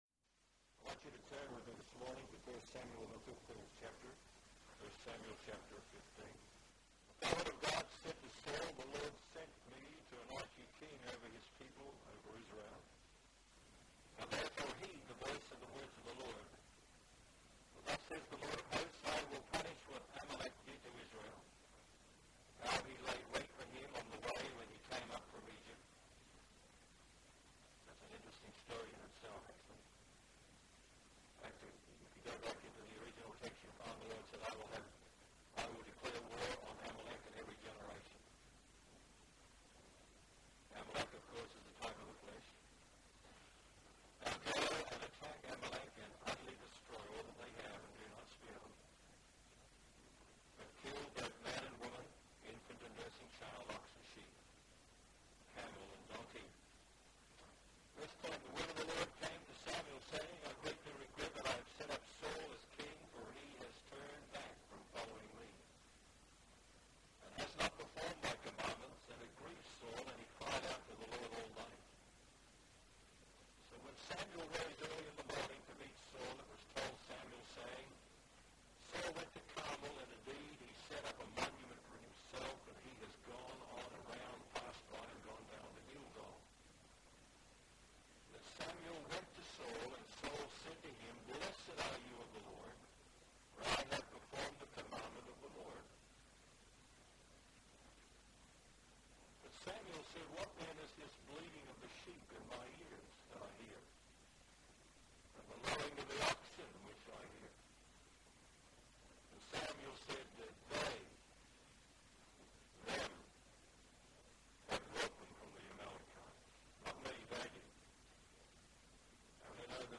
A prophetic sermon